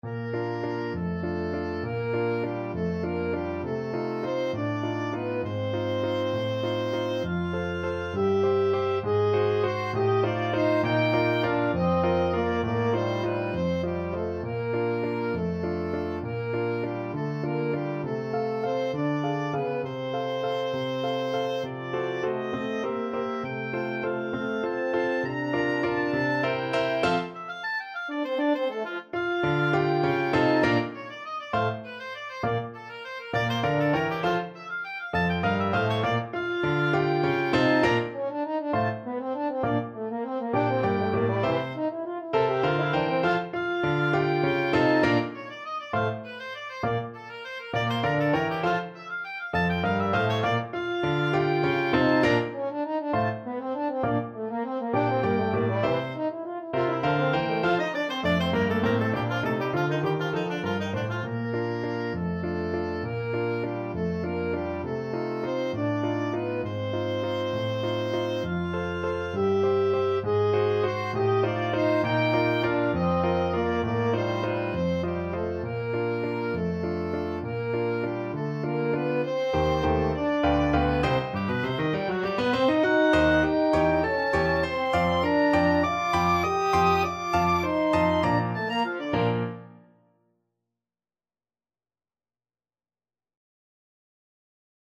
OboeClarinet
3/4 (View more 3/4 Music)
~=200 One in a bar